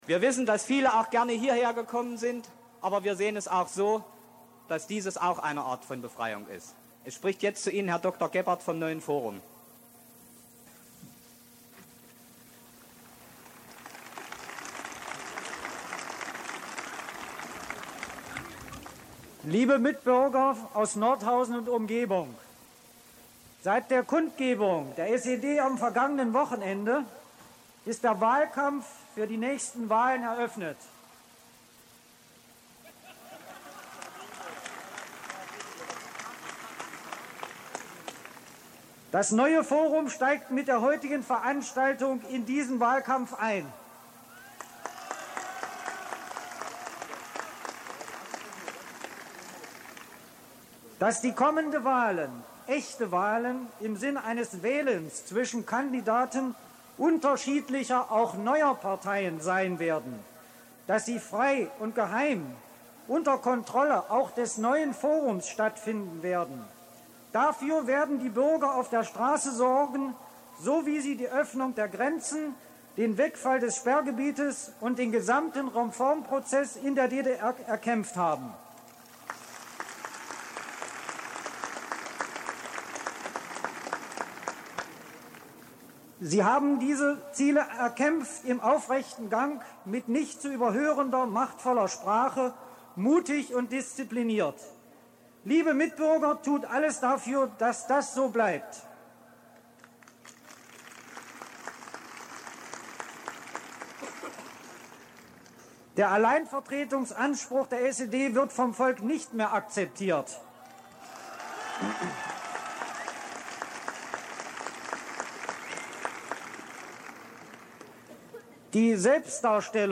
Demo auf dem August-Bebel-Platz am 14. November 1989